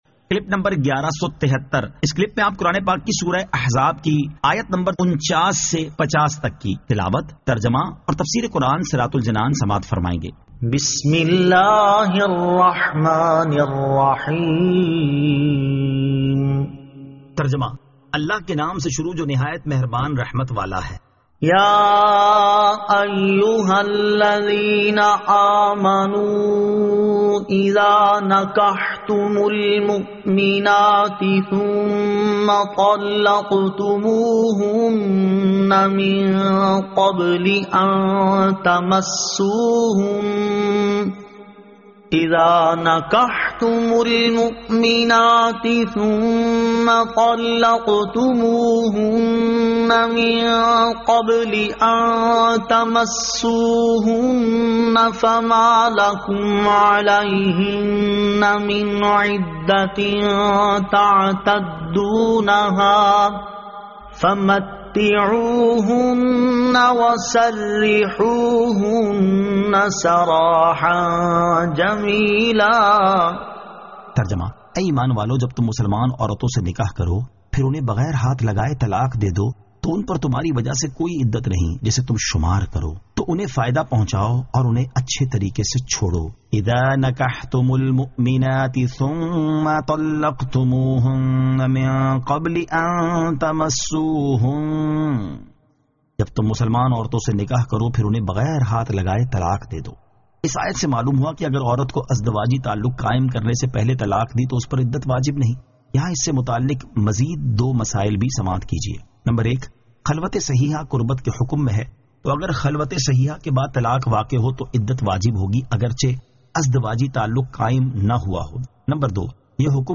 Surah Al-Ahzab 49 To 50 Tilawat , Tarjama , Tafseer